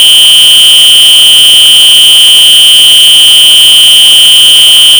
That’s your model hesitating. That jagged clipping is the sound of a system trying to say something complex that its architecture was never designed for.